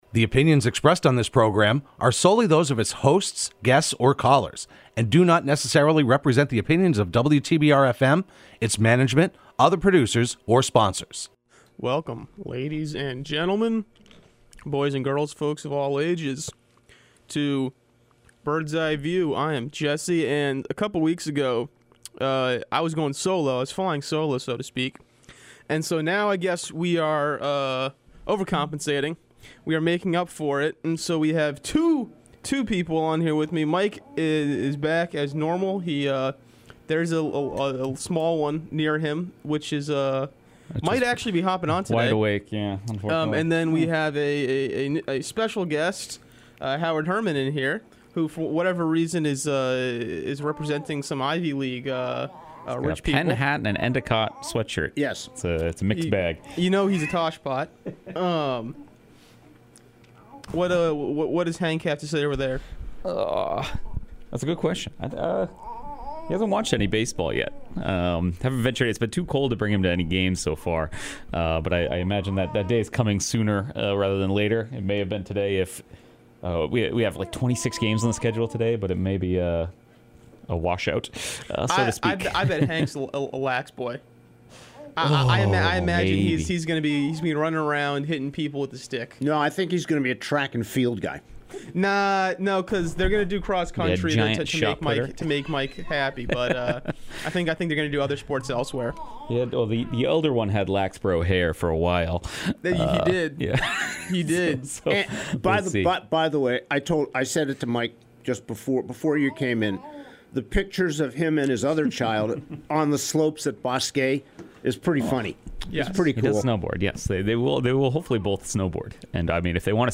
Broadcast live every Thursday morning at 10am.